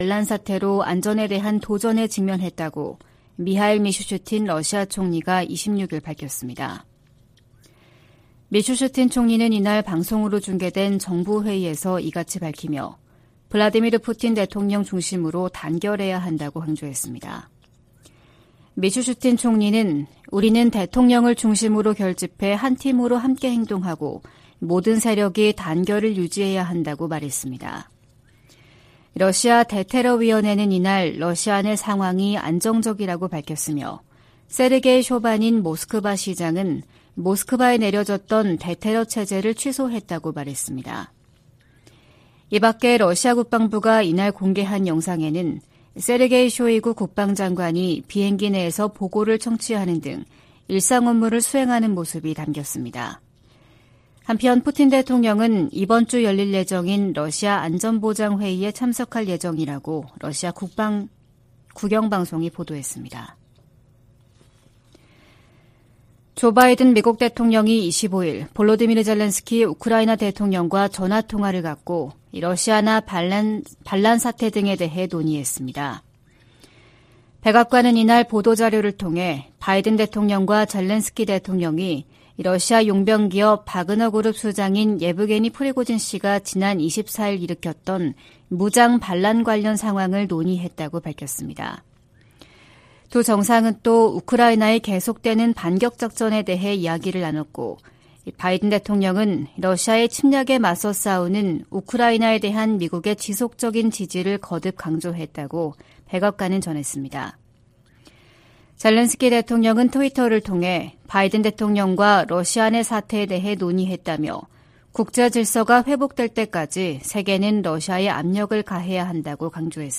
VOA 한국어 '출발 뉴스 쇼', 2023년 6월 27일 방송입니다. 북한 동창리 서해위성발사장의 새 로켓 발사대 주변에서 새로운 움직임이 포착돼 새 발사와의 연관성이 주목됩니다. 미국은 중국에 대북 영향력을 행사할 것을 지속적으로 촉구하고 있다고 백악관이 밝혔습니다. 국무부는 북한의 식량난이 대북 제재 때문이라는 러시아 대사의 주장에 대해 북한 정권의 책임을 다른 곳으로 돌리려는 시도라고 비판했습니다.